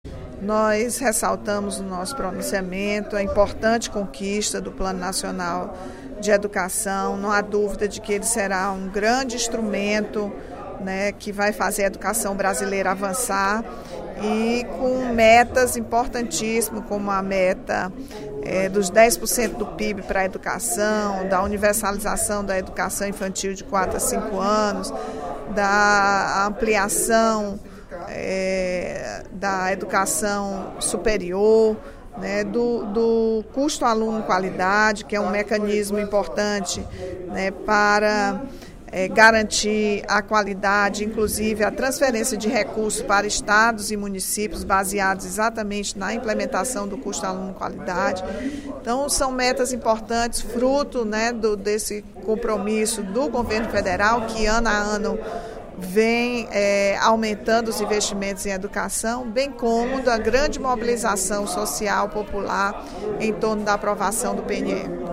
A deputada Rachel Marques (PT), em pronunciamento no primeiro expediente da sessão plenária desta quarta-feira (04/06), comemorou a aprovação ontem do Plano Nacional de Educação (PNE) pelo Congresso Nacional.